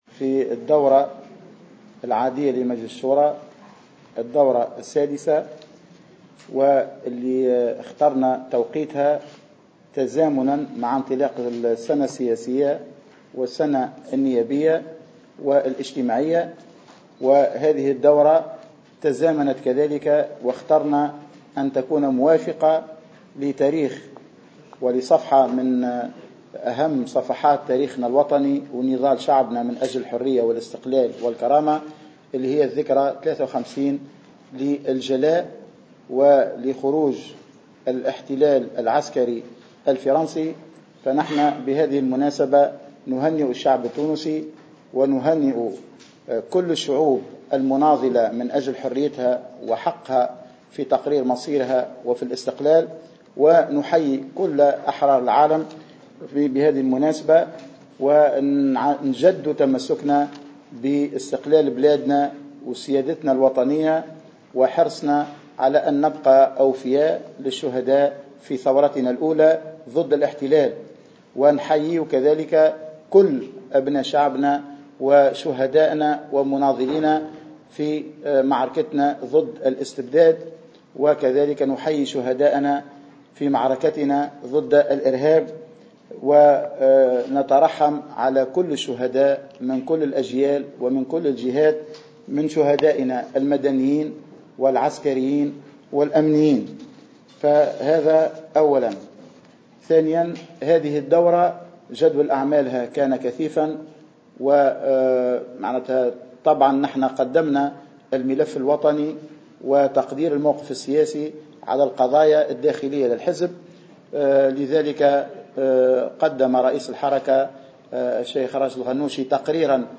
وأعلن الهاروني، في لقاء إعلامي على هامش انعقاد الدورة السادسة لمجلس شورى الحركة السبت والاحد بالحمامات، أن المجلس قرر عقد دورة استثنائية للمجلس تخصص للتعمق في مشروعي قانون المالية وميزانية الدولة لسنة 2017 قبل مناقشتهما وقبل تنظيم مؤتمر الاستثمار بالدولي.